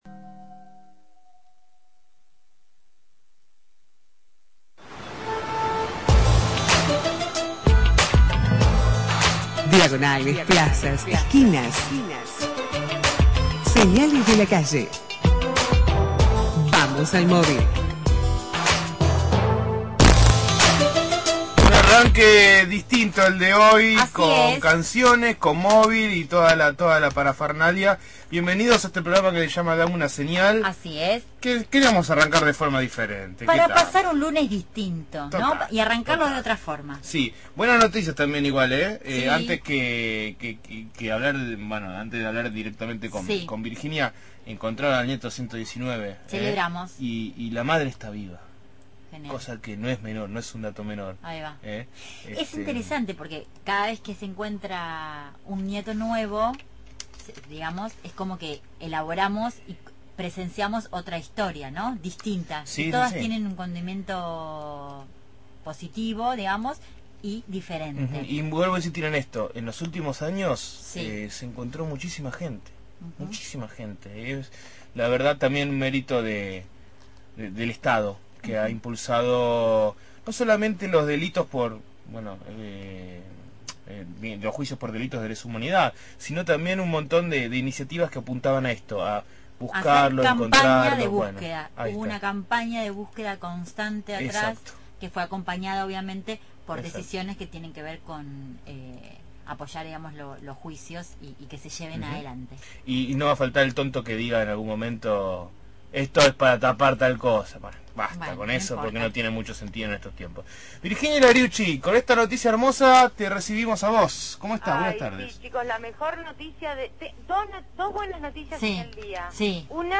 MÓVIL/ Conflicto UTA La Plata – Radio Universidad